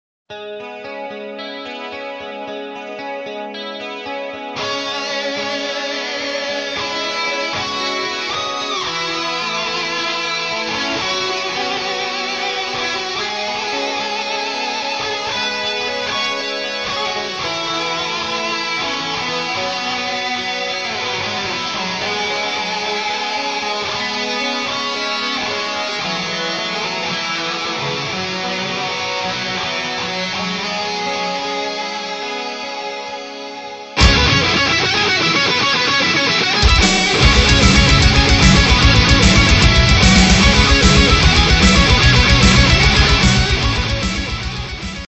Heavy/Power Metal